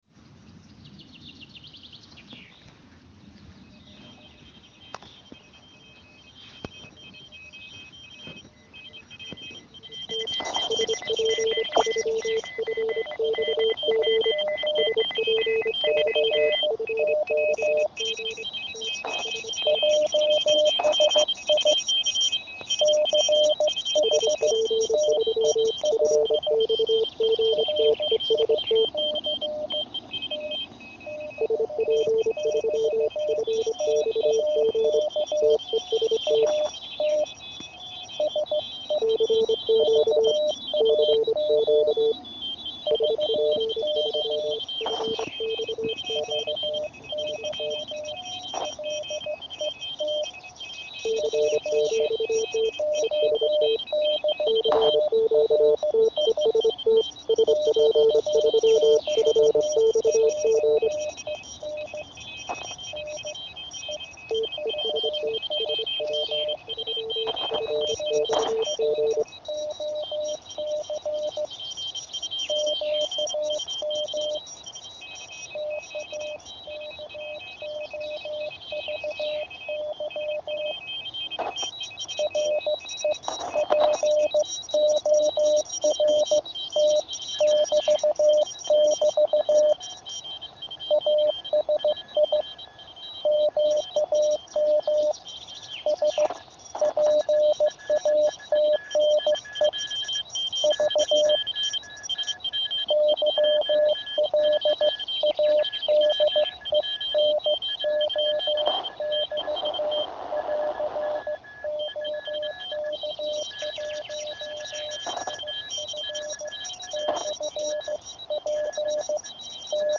Používal jsem výkonu asi 2W a anténa byla klasická z mojí SOTA výbavy - LW20m. V době testu probíhal polský závod a na kmitočtu 7030 KHz byla nějaká polská stanice.
Spojení 2x CRK-10A (mp3)